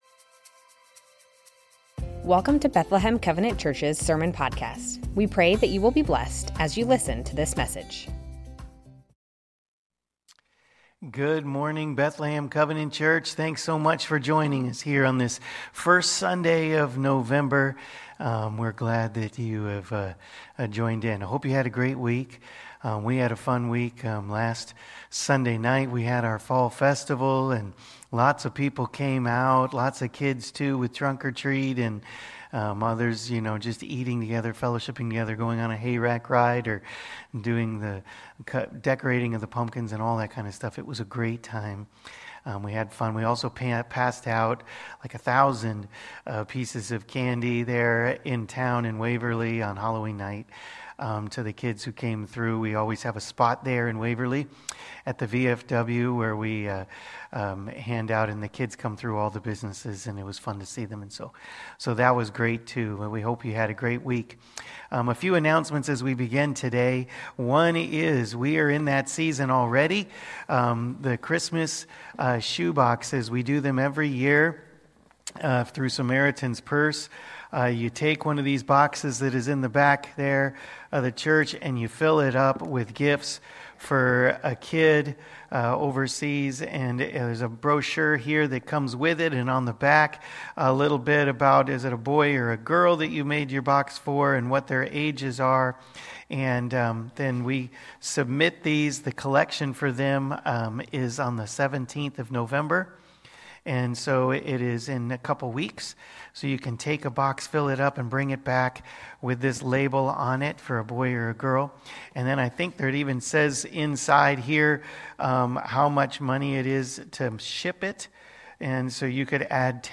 Bethlehem Covenant Church Sermons The Servant Songs - Isaiah 49:1-7 Nov 03 2024 | 00:35:04 1x 00:00 / 00:35:04 Subscribe Share Spotify RSS Feed Share Link Embed